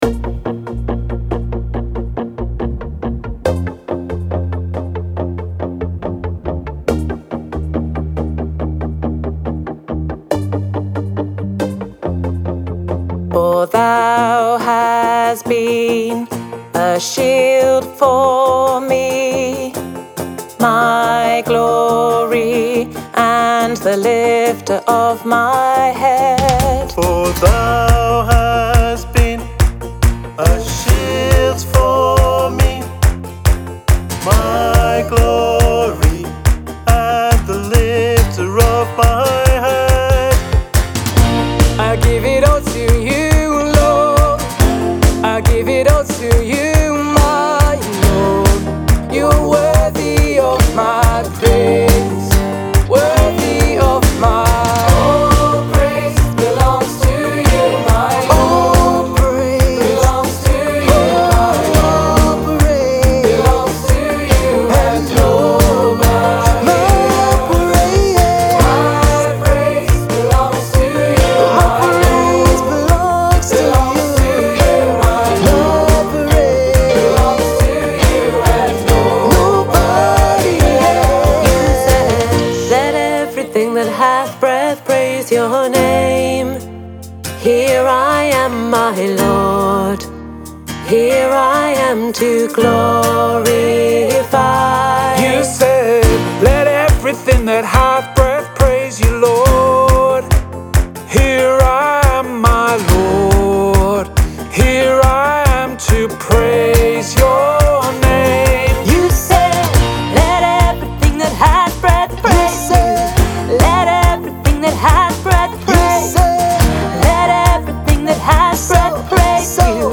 Check out this song, it's a collaboration between our worship teams as an act of worship.